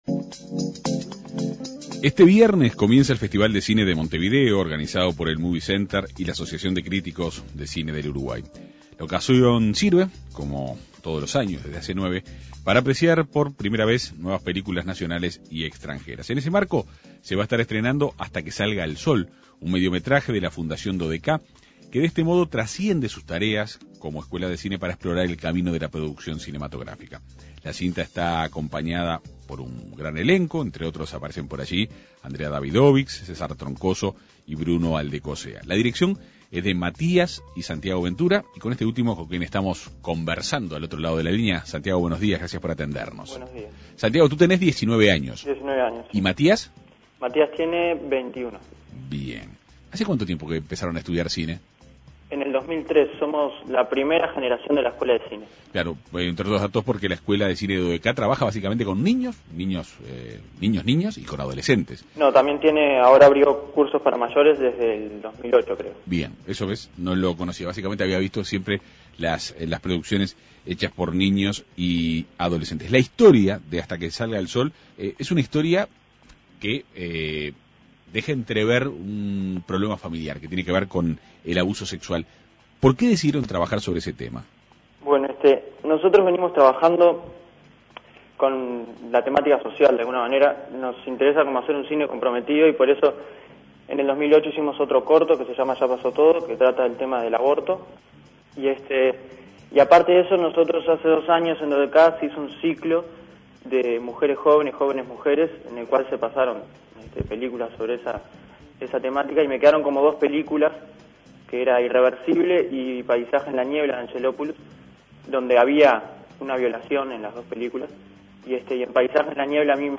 conversó en la Segunda Mañana de En Perspectiva.